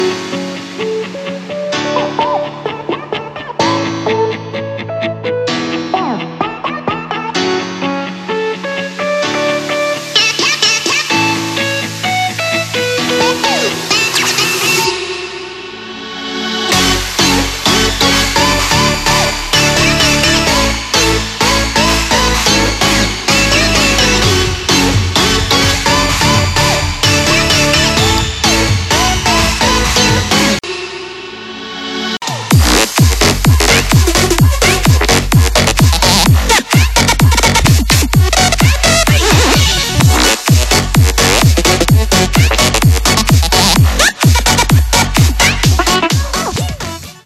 • Качество: 320, Stereo
ритмичные
громкие
dance
Electronic
club
Big Room
забавный голос
Bass
electro house
Melbourne Bounce